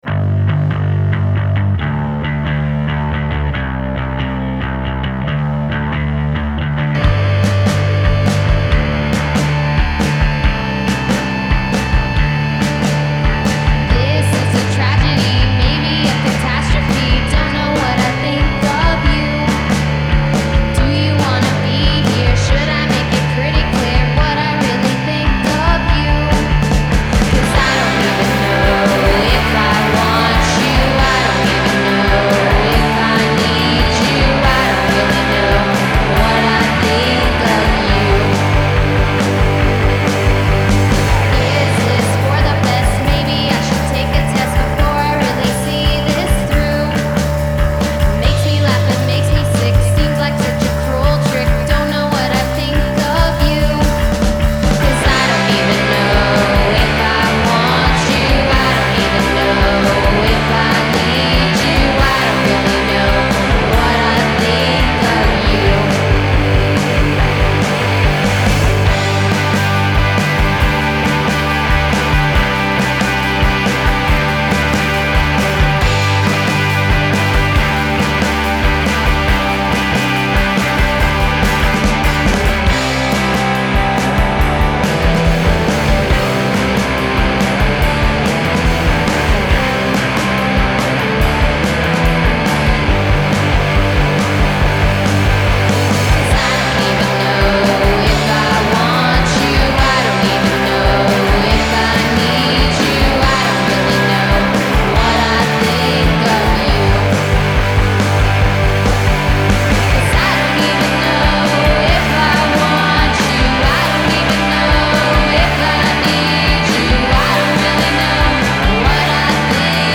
Loud, poppy and fun fun fun!
Vocals / Guitar
Bass
Drums / Backing Vocals
Lead Guitar